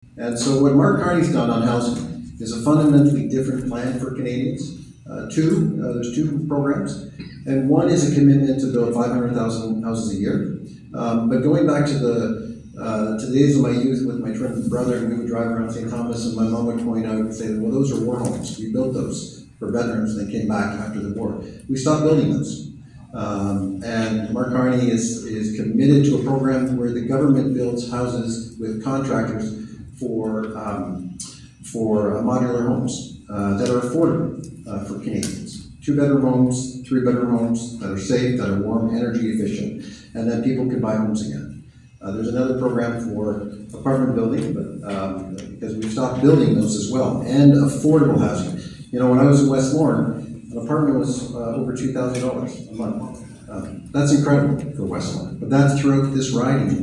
The final all-candidate debate/meet and greet was held Wednesday at the Keystone Complex in Shedden with all four candidates participating, fielding pre-selected, ag-related questions.